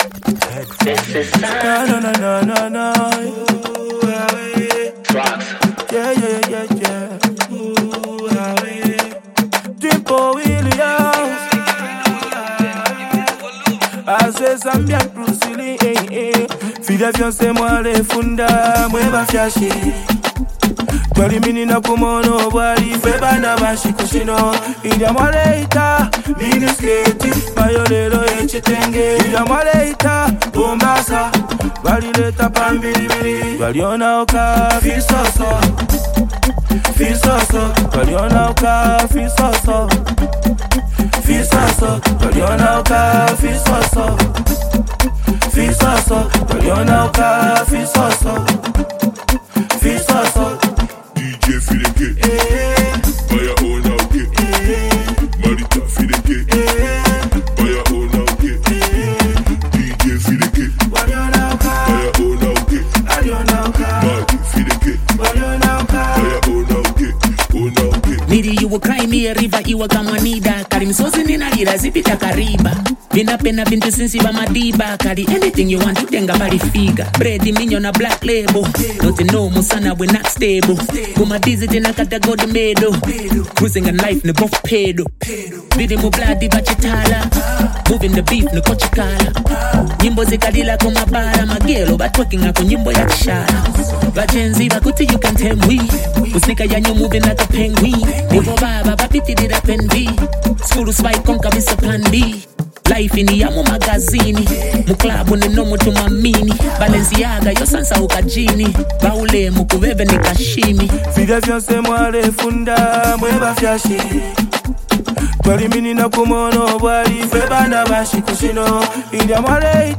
smooth Afrobeat rhythms with heartfelt emotions